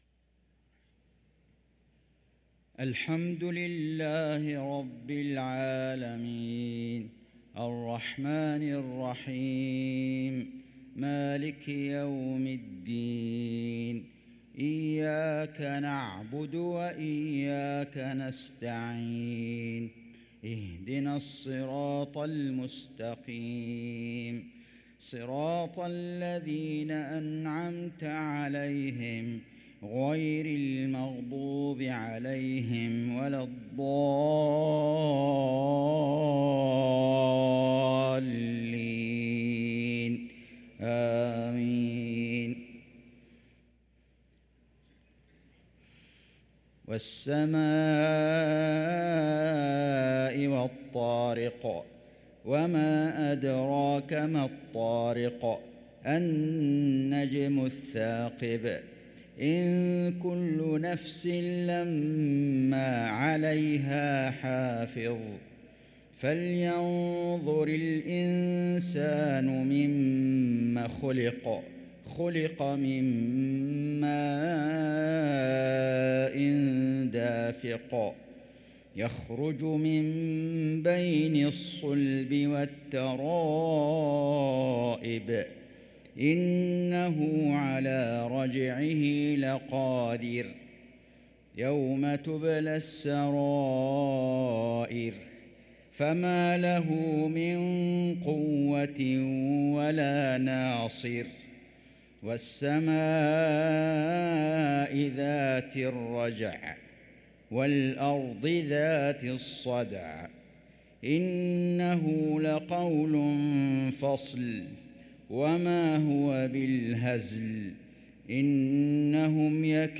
صلاة المغرب للقارئ فيصل غزاوي 10 ربيع الآخر 1445 هـ
تِلَاوَات الْحَرَمَيْن .